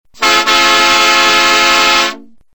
Car Horn 4